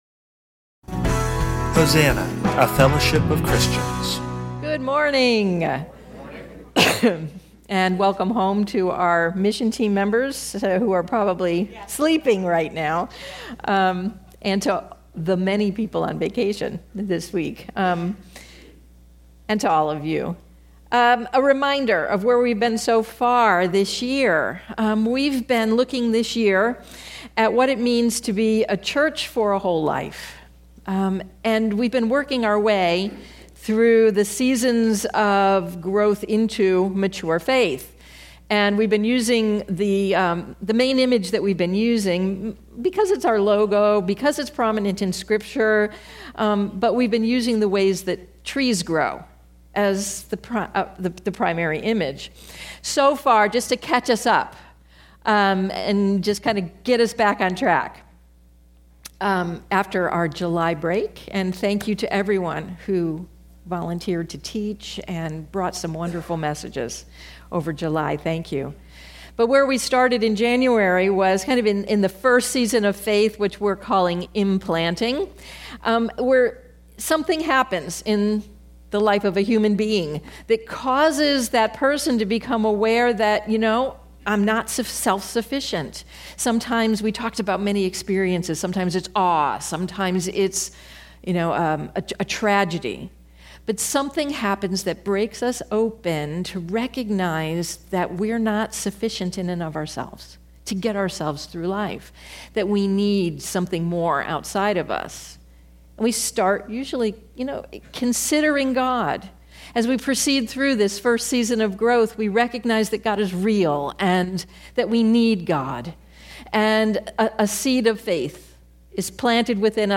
Genre: Spoken Word.